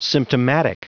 Prononciation du mot symptomatic en anglais (fichier audio)
Prononciation du mot : symptomatic